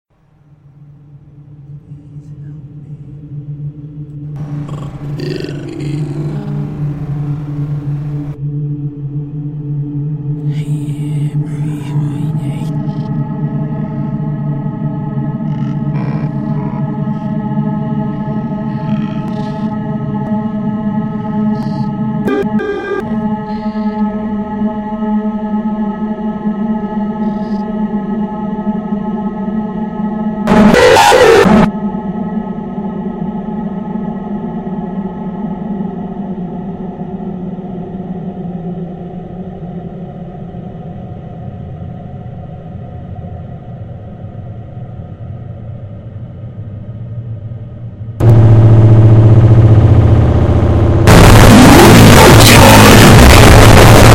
The page now contained a distorted version of "Foggyworld248.jpg" titled "reunion.png" and a corrupted audio file titled "threevoices.mp3".
Threevoices.mp3